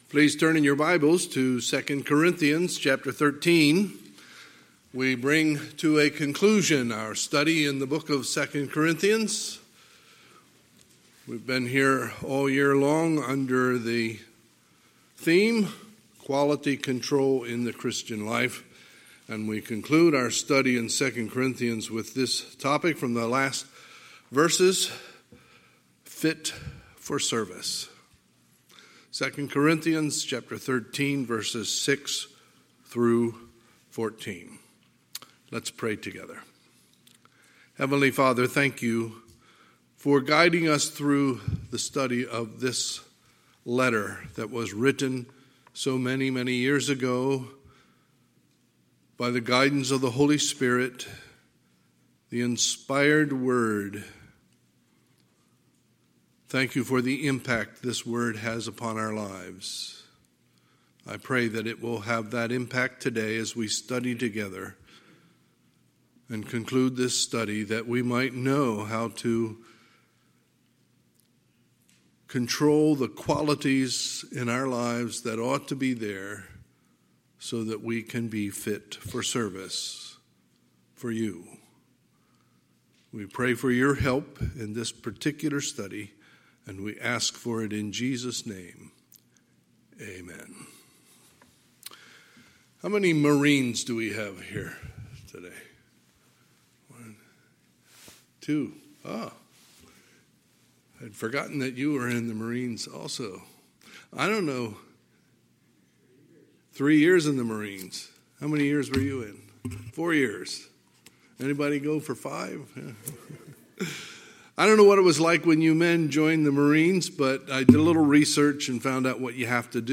Sunday, November 29, 2020 – Sunday Morning Service